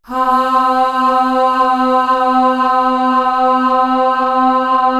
Index of /90_sSampleCDs/Best Service ProSamples vol.55 - Retro Sampler [AKAI] 1CD/Partition C/CHOIR AHH